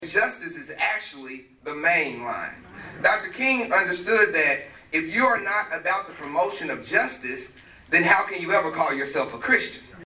Monday morning, the community gathered at HCC to honor Dr. Martin Luther King Jr.’s vision for justice and equality.